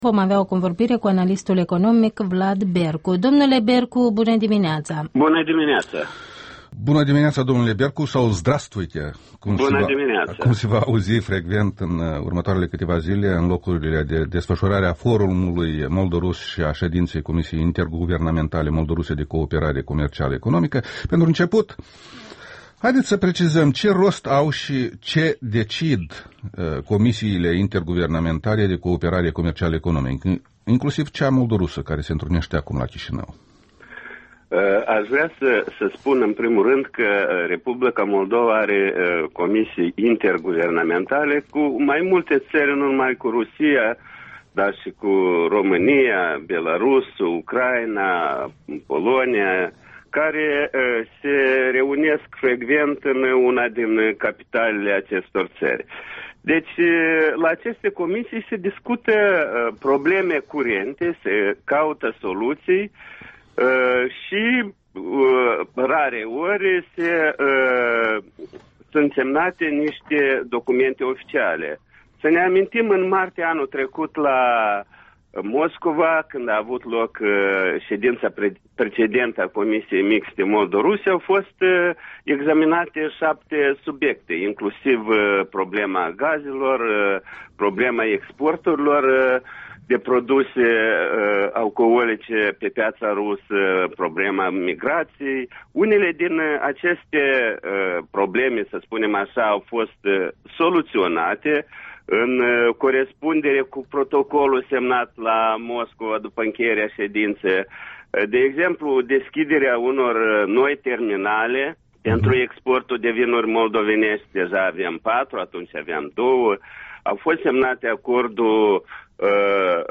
Interviul matinal la REL